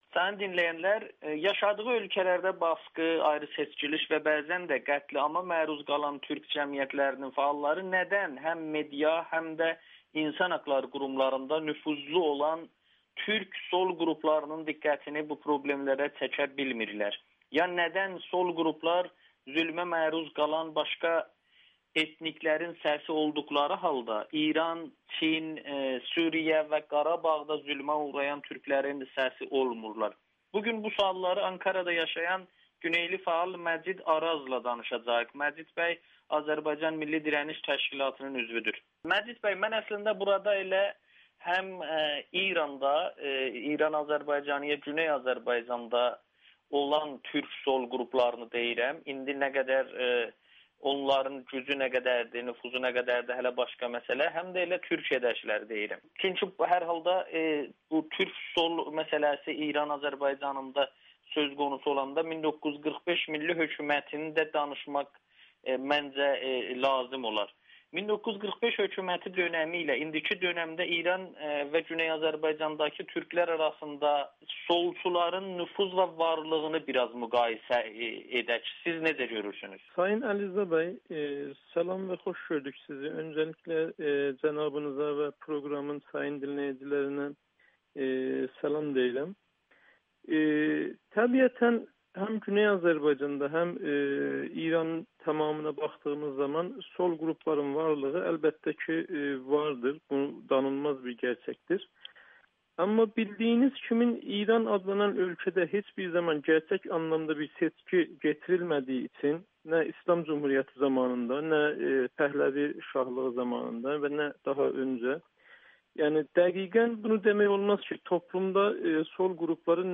Siyasi qarşıdurmalarda insan haqlarını unuduruq [Audio-Müsahibə]